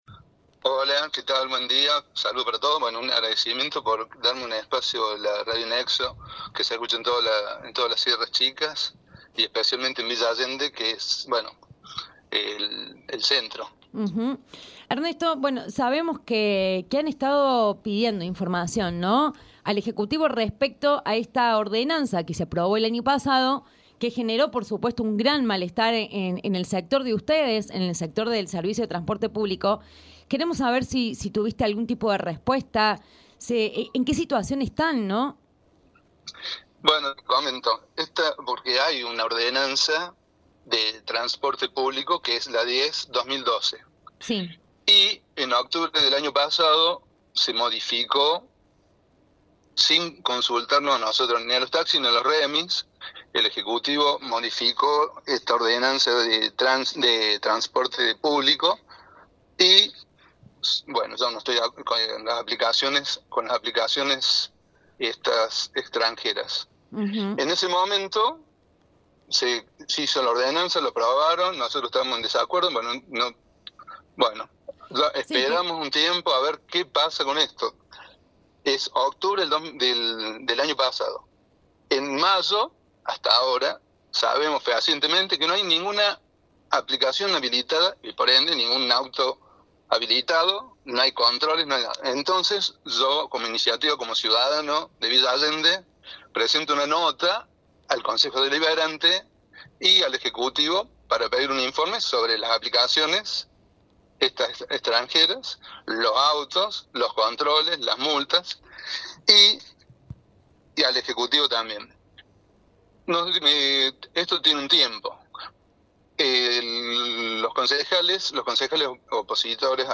Sin respuesta En entrevista con Nexo FM